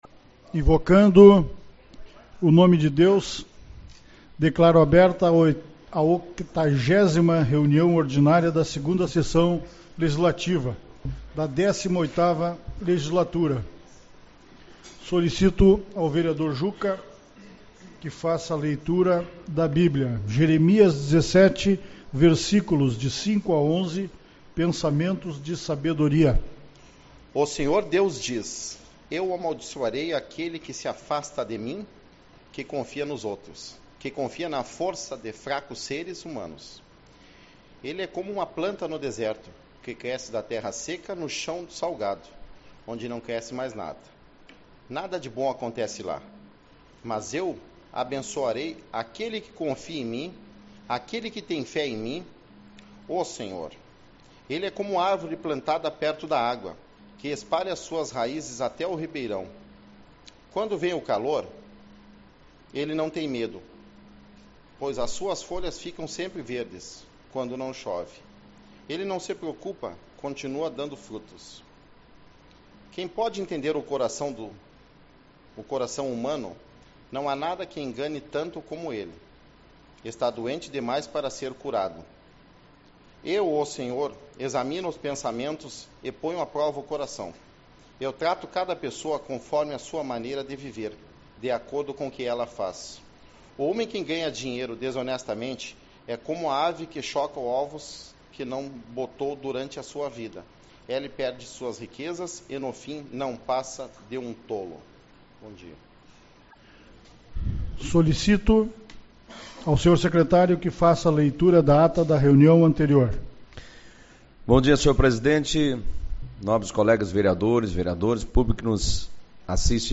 08/12 - Reunião Ordinária